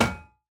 Minecraft Version Minecraft Version 1.21.5 Latest Release | Latest Snapshot 1.21.5 / assets / minecraft / sounds / block / heavy_core / break1.ogg Compare With Compare With Latest Release | Latest Snapshot
break1.ogg